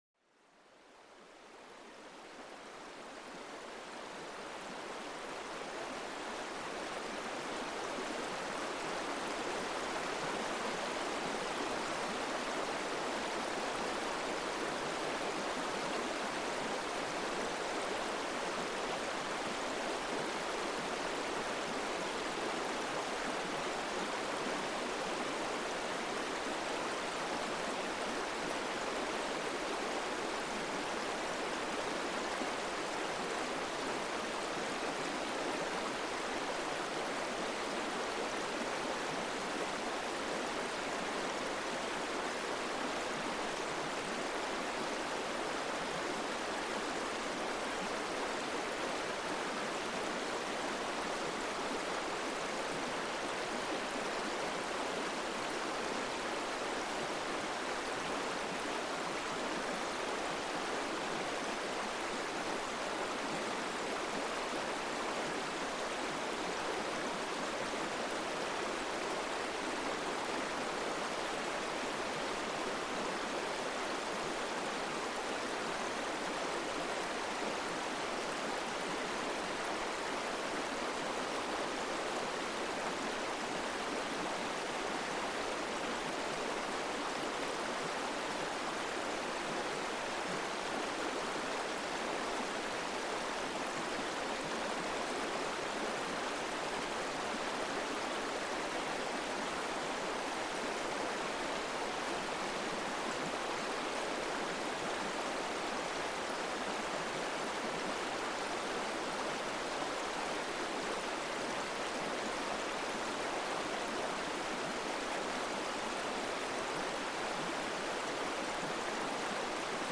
Plongez dans l'ambiance sereine d'un courant de rivière, où chaque goutte d'eau raconte une histoire de calme et de renouveau. Laissez-vous emporter par le son hypnotique de l'eau s'écoulant doucement sur les pierres, un véritable baume pour l'âme en quête de tranquillité.